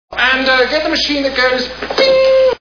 Monty Python's Meaning of Life Movie Sound Bites